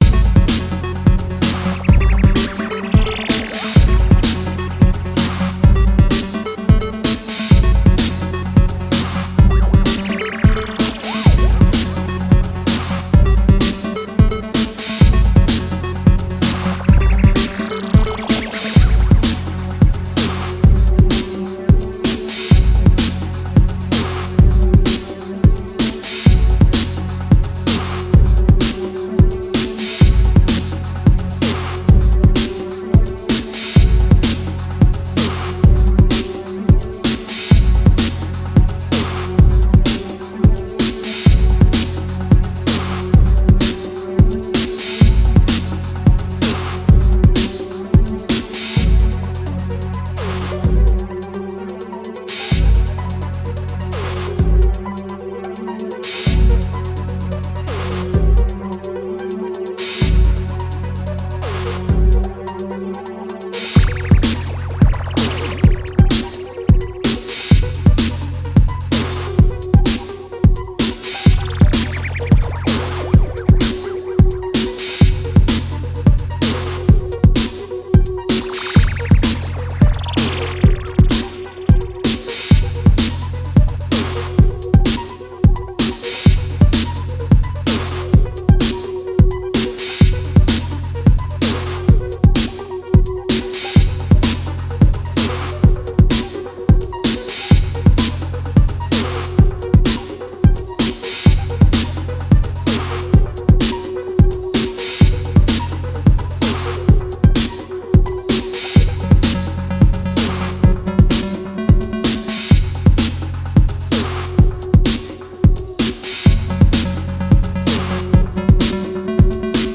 Techno and Electro jams